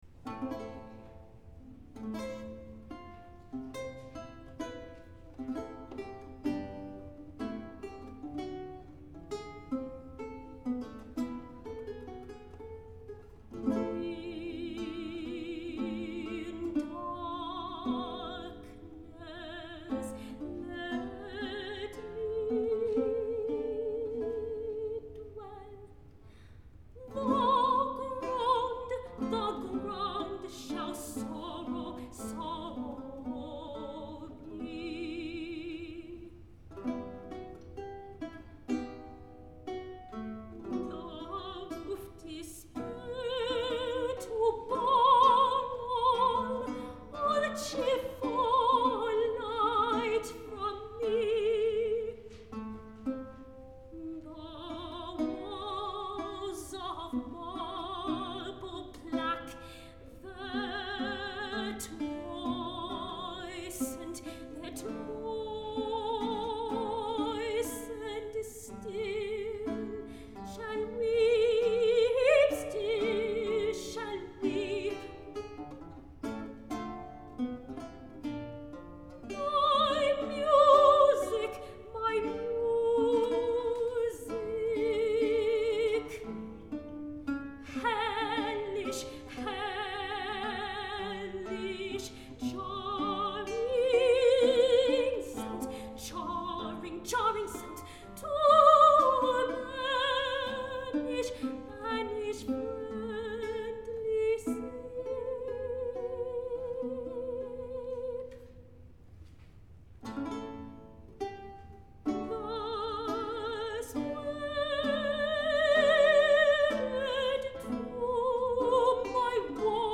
Soprano
Lute
*live performances